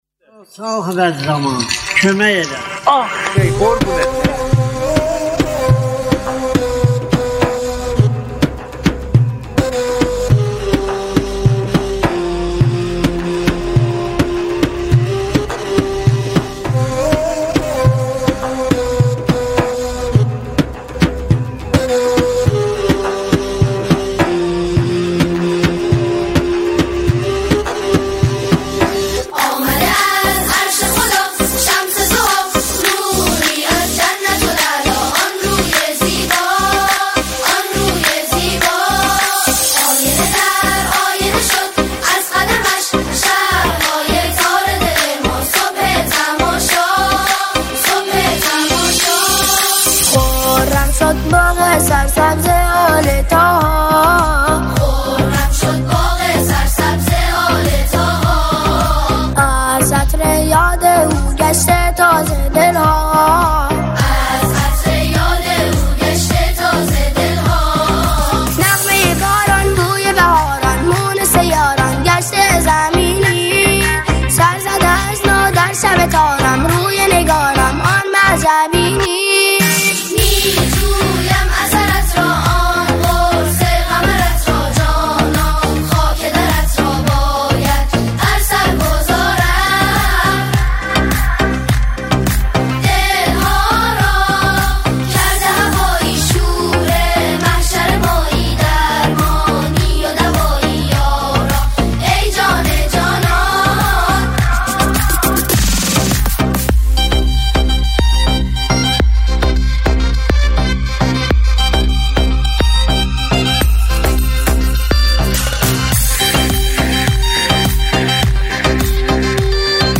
سرودهای امام زمان (عج)